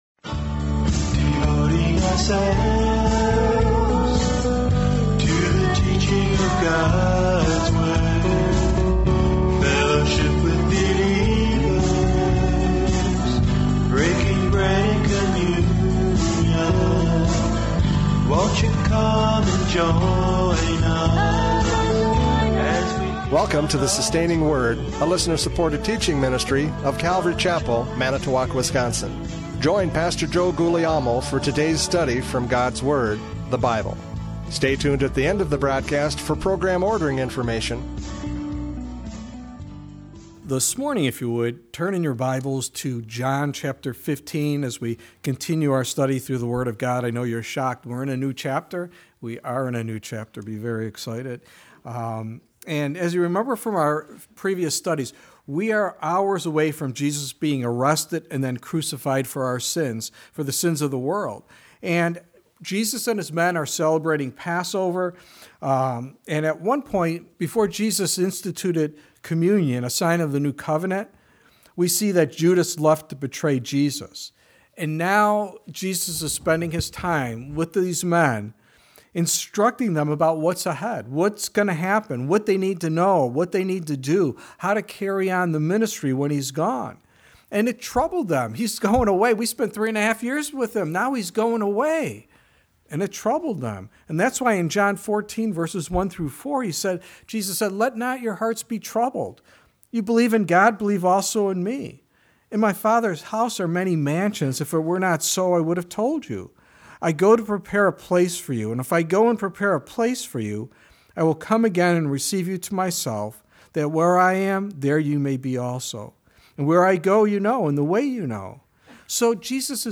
John 15:1-11 Service Type: Radio Programs « John 14:22-31 The Teacher!